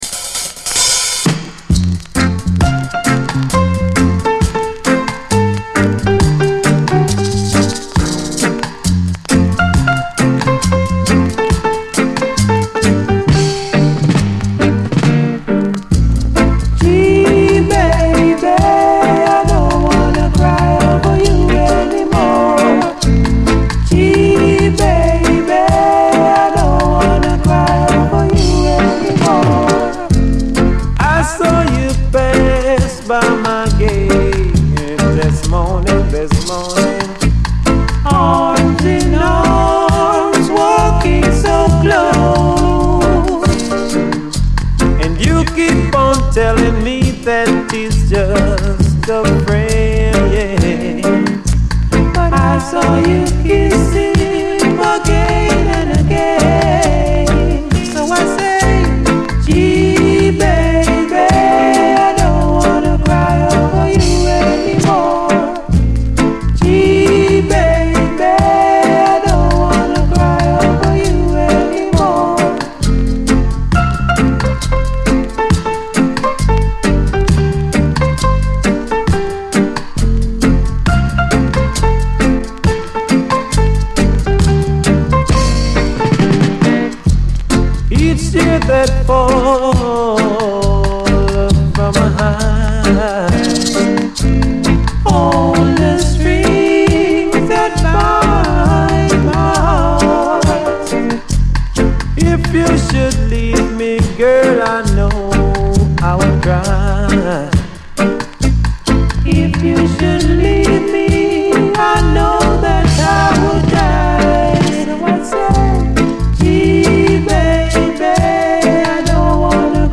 REGGAE, 7INCH
ジャマイカン・ラヴァーズ〜ロックステディー名曲
甘いコーラス＆メロディーが非常にドリーミーでロマンティック、トロける一曲！